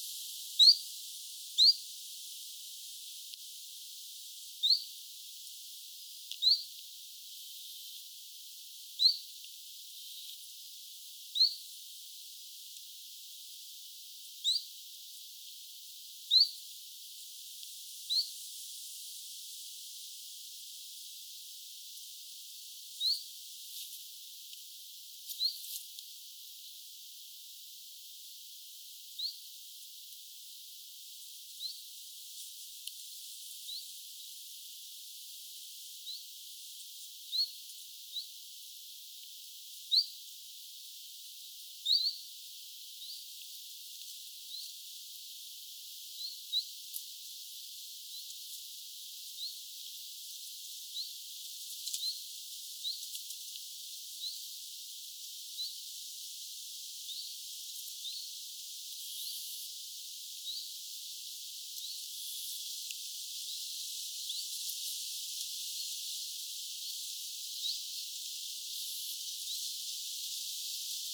Ne ääntelivät lähinnä vit-äänillä.
Video: keltatiltaltti, vit-tiltaltti
tällaisia ääniä ne kolme päästelivät
kolme_vit-tiltalttia_parvi.mp3